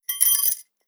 Danza árabe, pulseras de aros de la bailarina 01
agitar
Sonidos: Acciones humanas